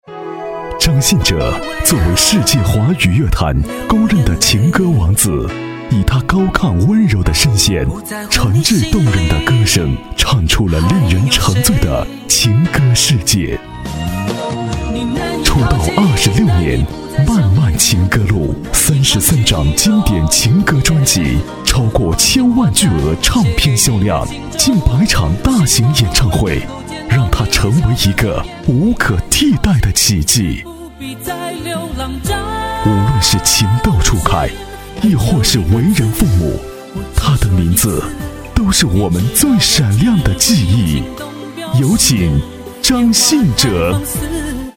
• 男S317 国语 男声 宣传片-张信哲演唱会南昌站-演唱会宣传片-沉稳抒情 沉稳|娓娓道来|积极向上